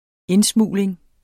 Udtale [ -ˌsmuˀleŋ ]